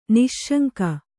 ♪ niśśaŋka